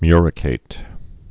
(myrĭ-kāt) also mu·ri·cat·ed (-kātĭd)